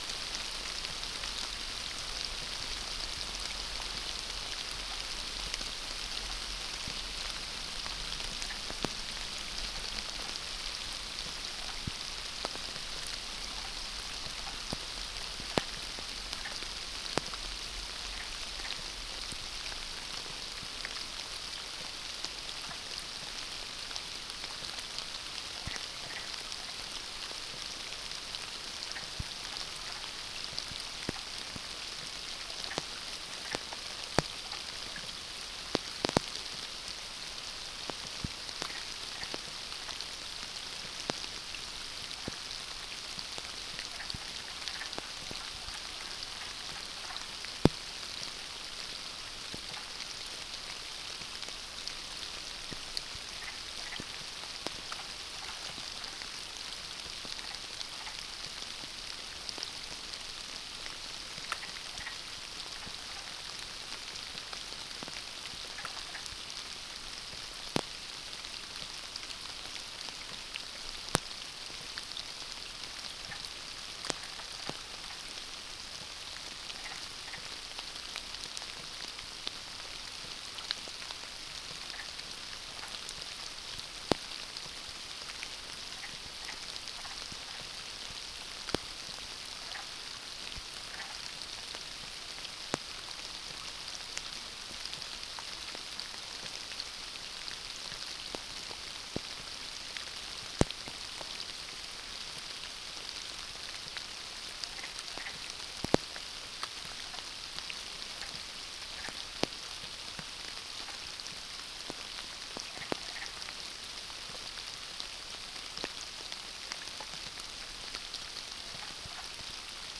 Late night sounds at this site in Talkeetna, Alaska, on May 19, 2010